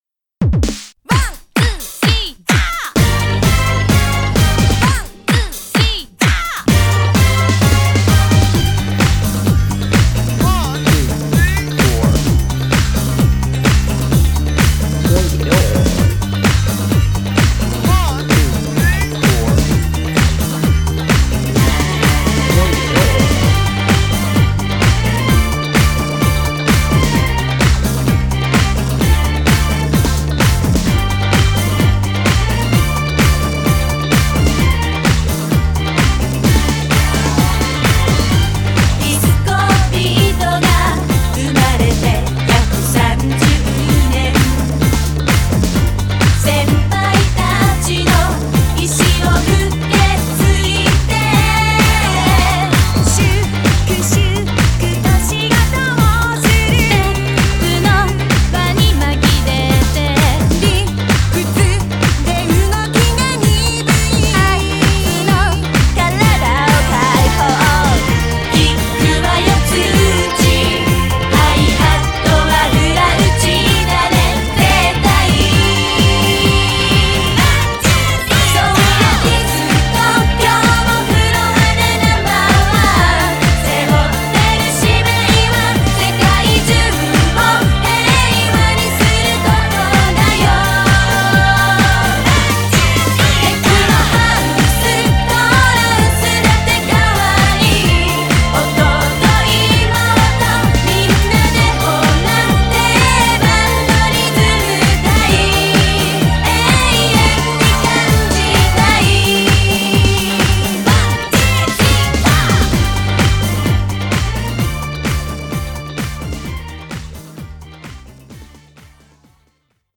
BPM129
Audio QualityPerfect (High Quality)
Genre: DISCO.